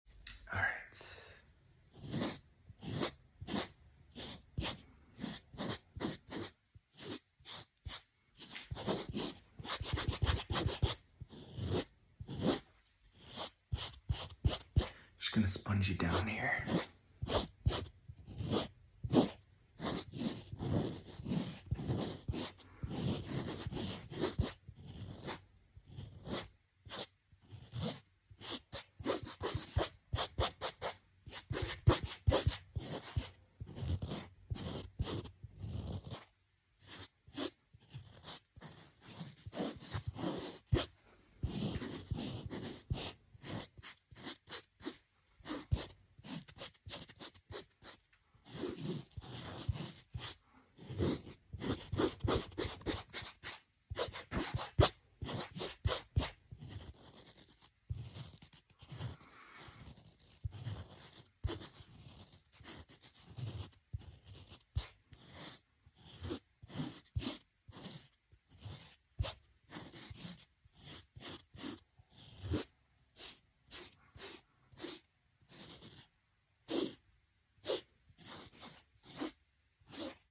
ASMR | I love these sound effects free download
ASMR | I love these sponge sounds